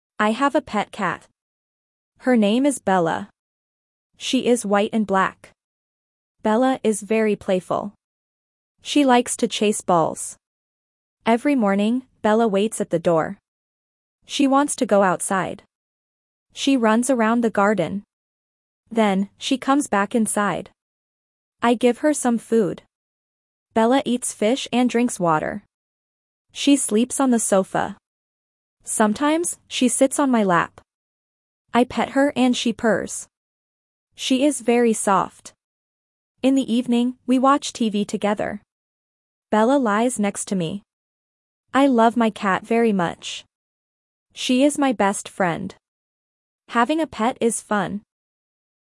Reading A1 - my pet